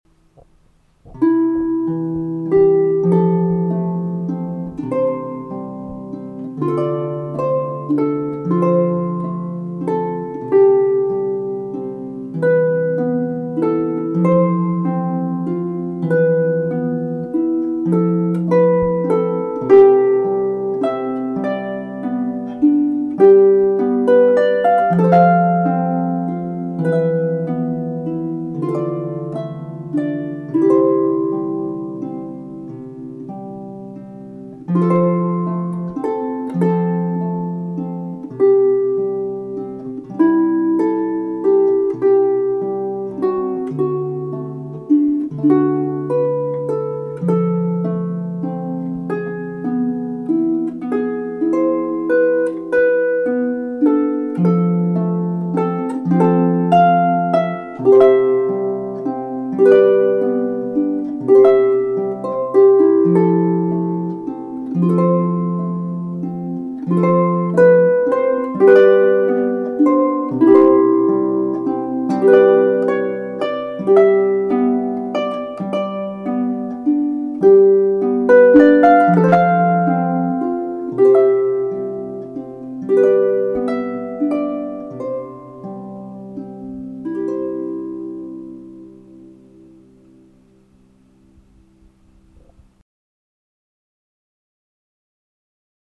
Harp music